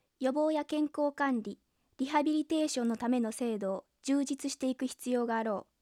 DAT(Digital Audio Tape)で収録した48kHz音源をリサンプルすることなくまとめたデータベースです。
オリジナルの音質を忠実に再現し、より豊かでクリアなサウンドを実現しています。
発話タスク ATR音素バランス503文
appbla_48k_F_sample.wav